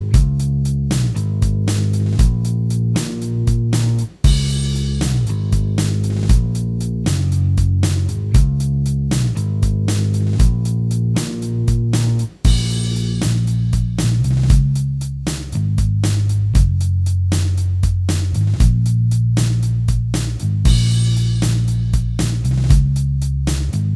Minus Guitars Rock 5:09 Buy £1.50